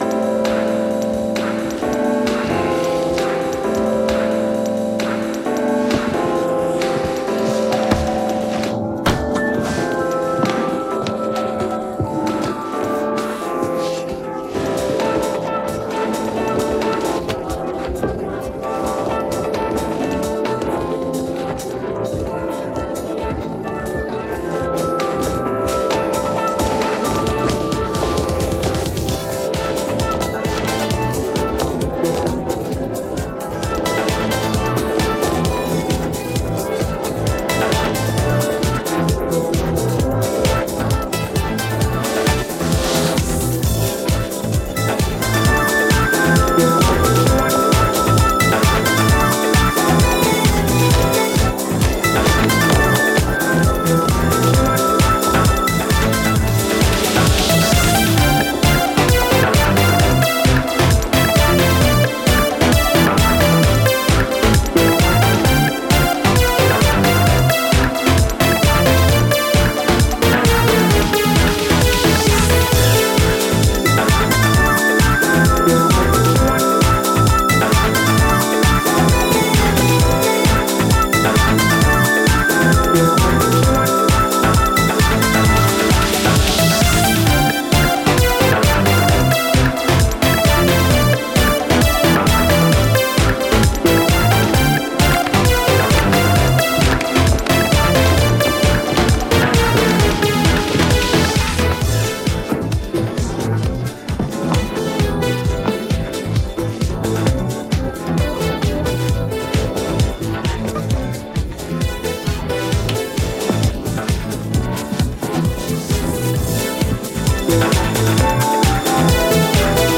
Это дорожка из сериала с убранной речью